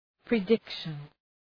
Προφορά
{prı’dıkʃən}